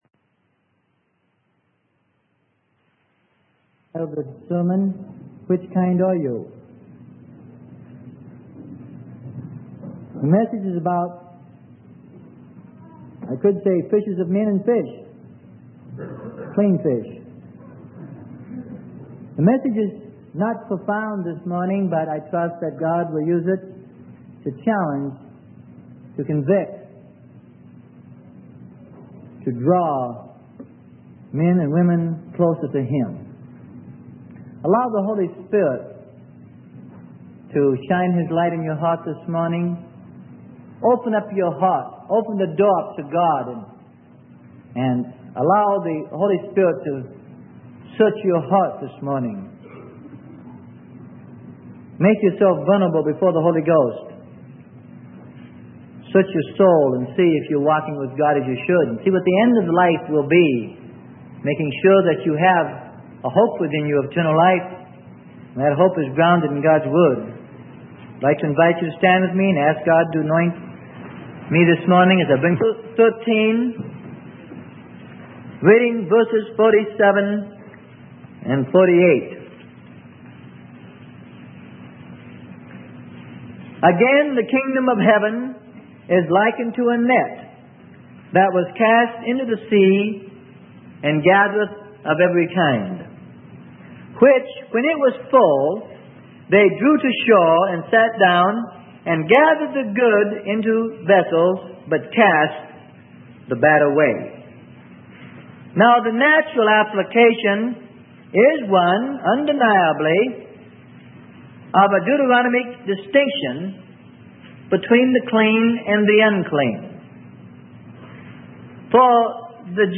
Sermon: The Gospel Net or What Kind Are You?